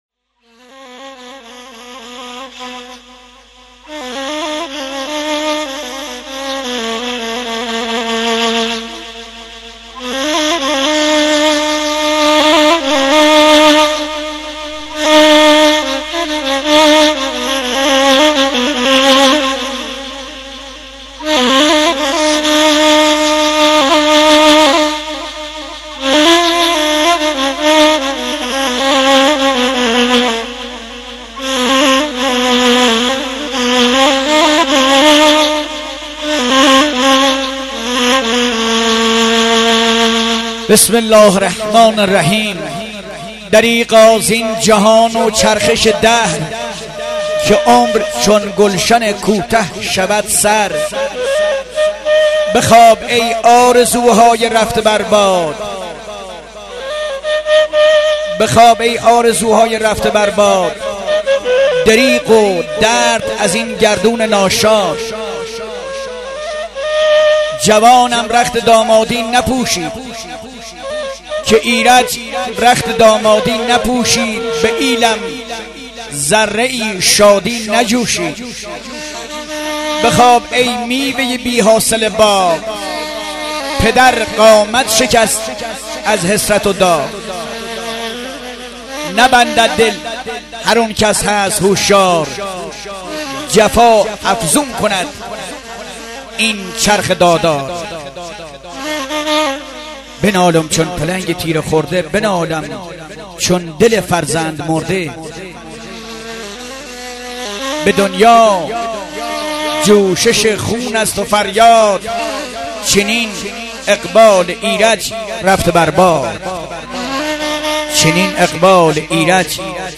دانلود عزاداری و سوگواری لری بختیاری
سبک ها: دندال (دوندال)، گاگریو – گویش: بختیاری